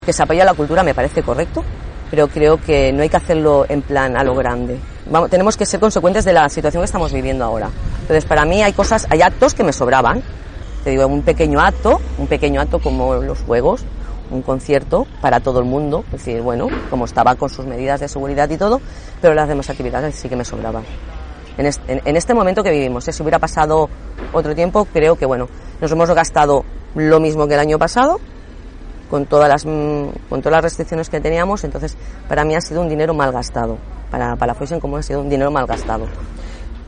La portaveu de Palafolls en Comú, Clara Hidalga, va passar ahir pel programa Assumptes Interns d’aquesta emissora, on va repassar l’actualitat política del nostre municipi. Des de la plaça de les Valls d’Ax, Hidalga va denunciar el que considera un atac continu del govern d’ERC.